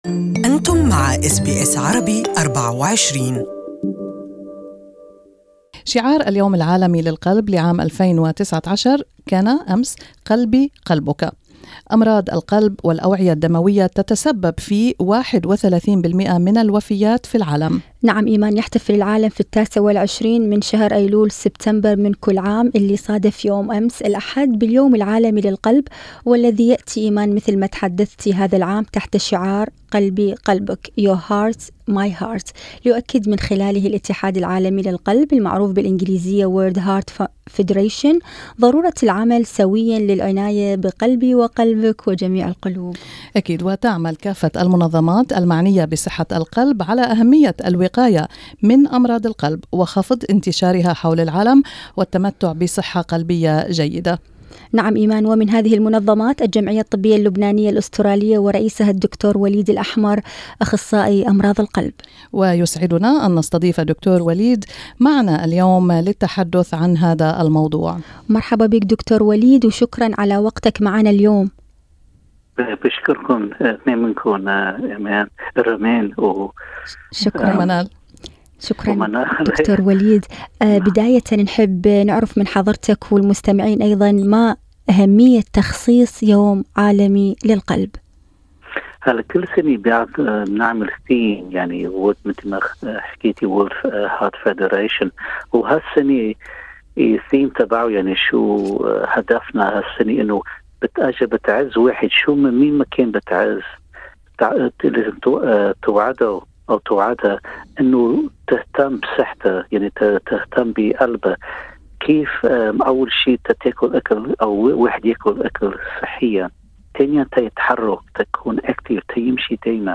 This is an interview